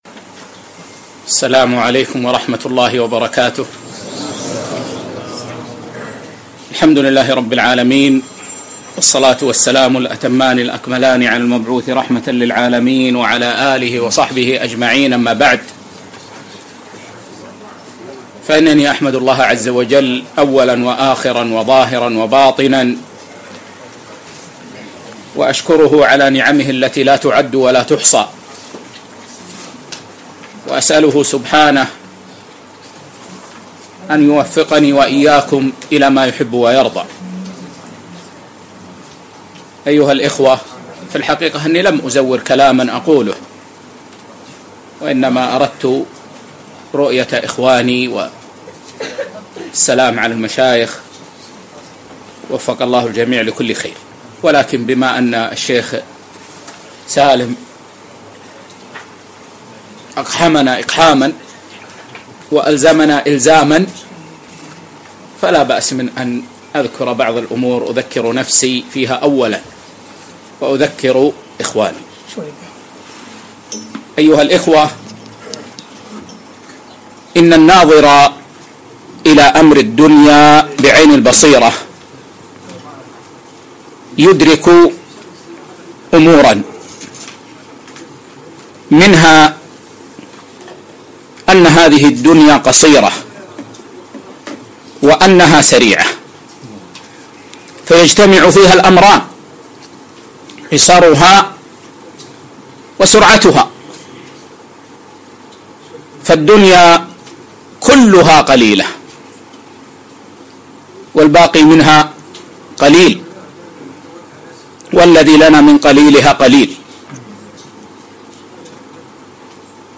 موعظة